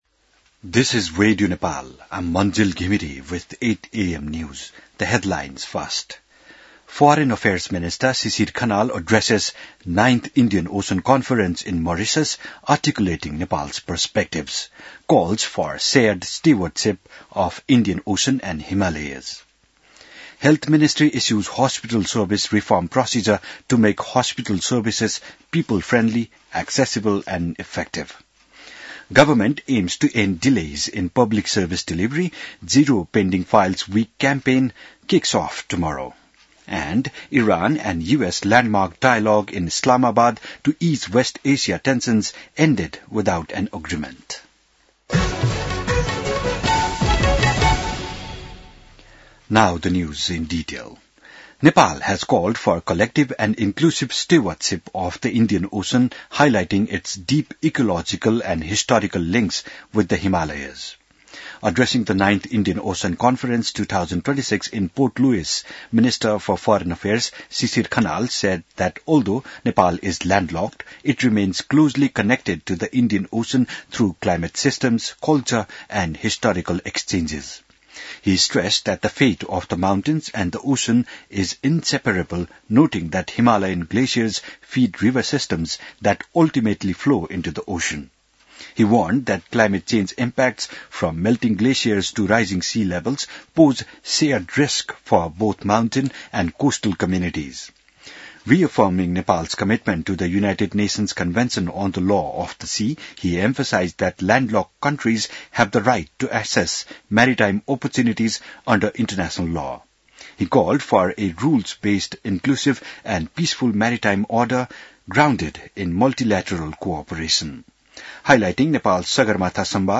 बिहान ८ बजेको अङ्ग्रेजी समाचार : २९ चैत , २०८२